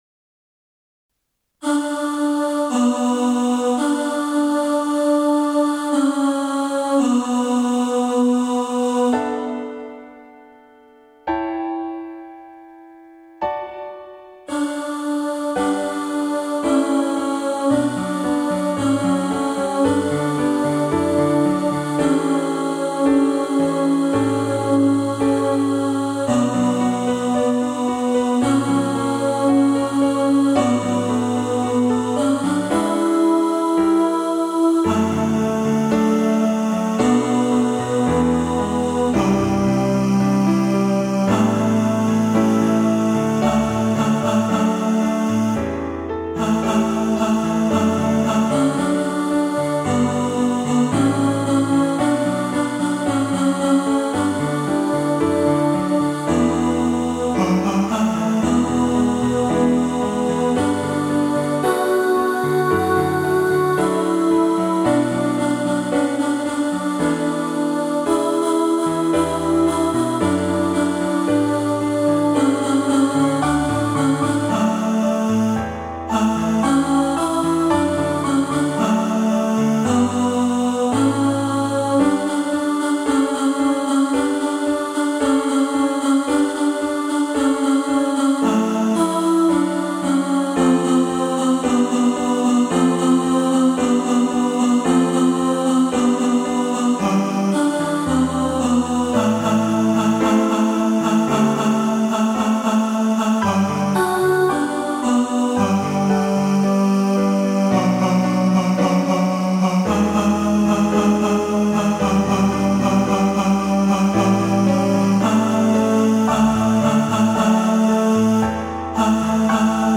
Proud-Of-Your-Boy-Tenor.mp3